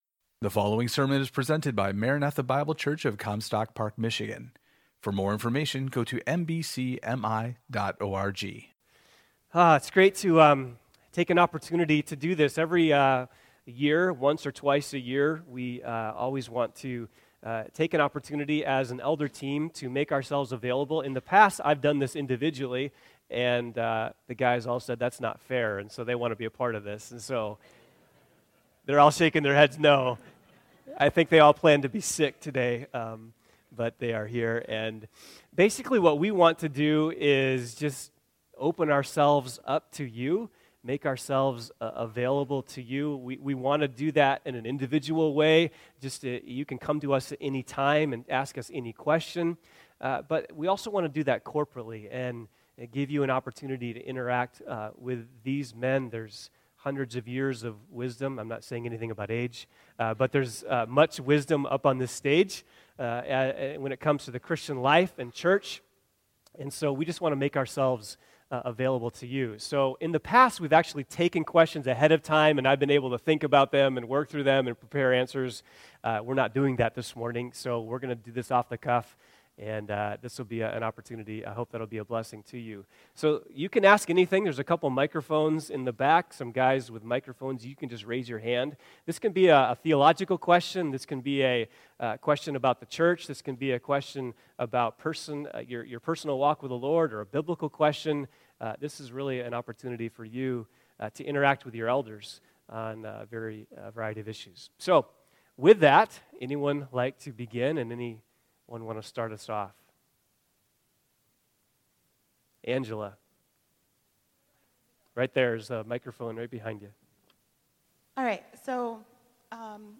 Elder Q&A
Question and Answer